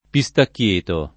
pistacchieto [ pi S takk L% to ]